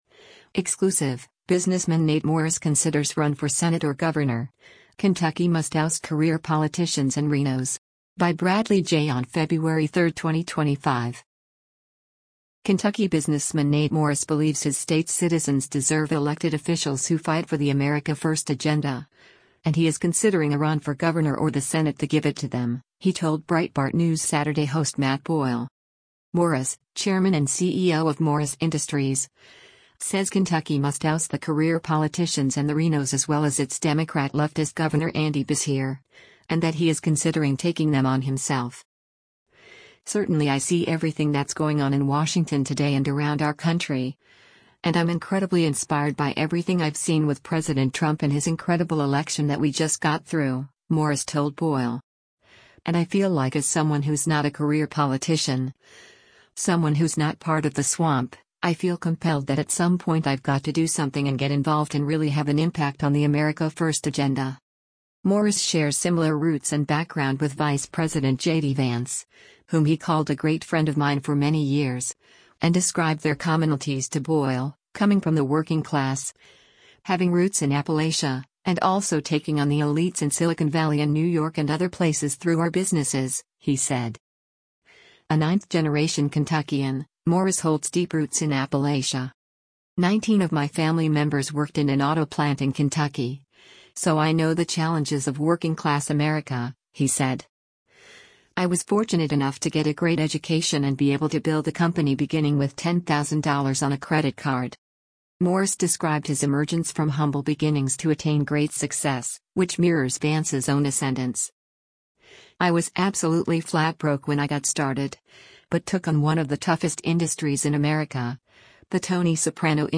Breitbart News Saturday airs on SiriusXM Patriot 125 from 10:00 a.m. to 1:00 p.m. Eastern.